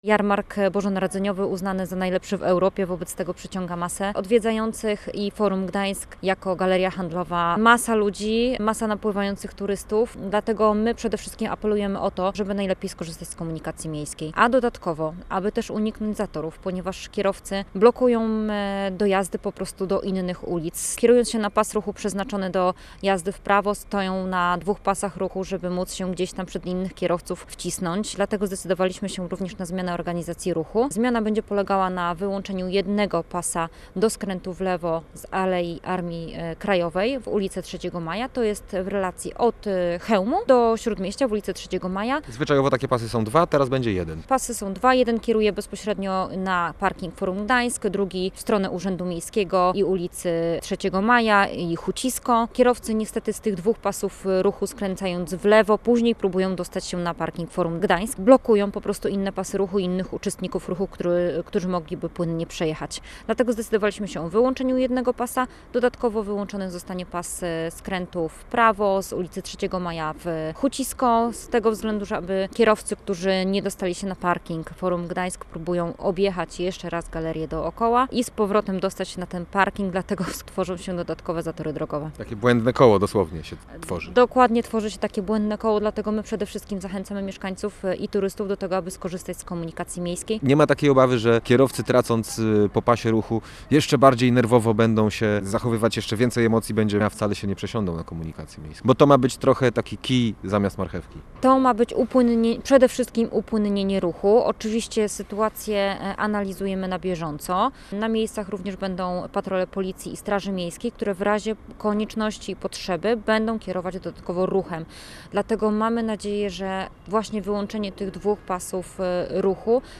Niezadowolonych kierowców i mieszkańców Gdańska